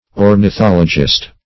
Ornithologist \Or`ni*thol"o*gist\, n.
ornithologist.mp3